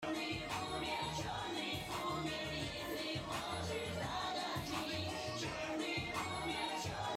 Mp3 Sound Effect Jeden Samstag 🇷🇺Music bei uns! diesen Samstag VINTAGE REVIVAL - Musik aus den 90er, 2000er sowie aktuelle Hits!